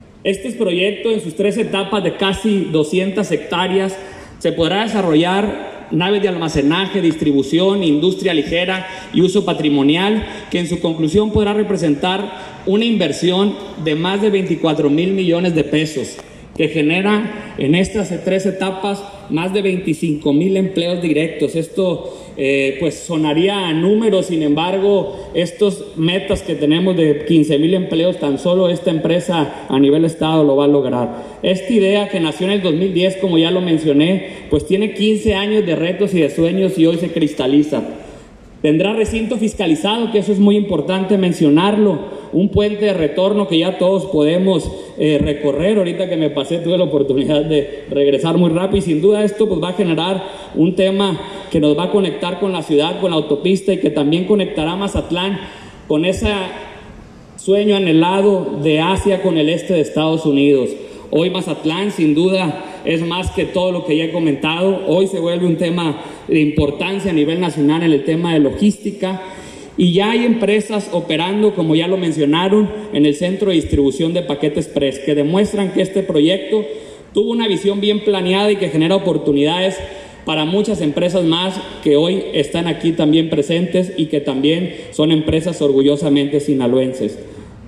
Ricardo-Velarde-Cardenas-secretario-de-Economia-de-Sinaloa-_-Inauguracion-de-MLC.mp3